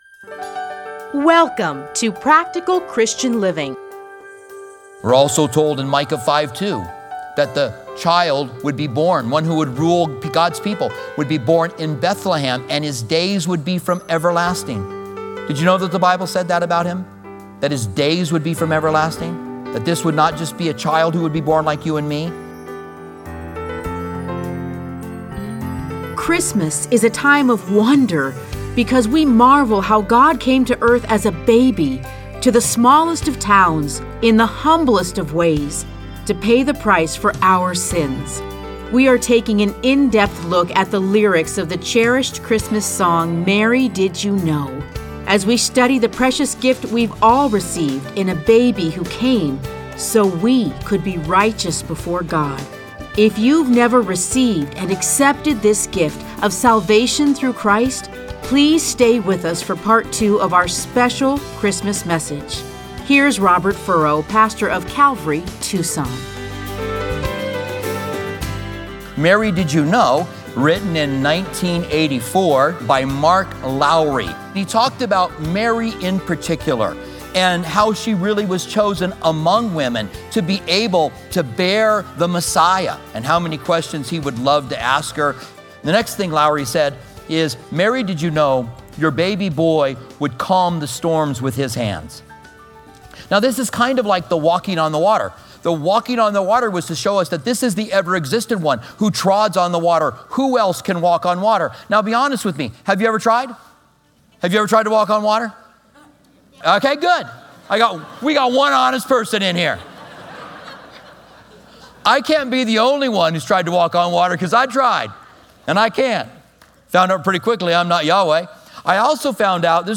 Listen to a teaching for Christmas.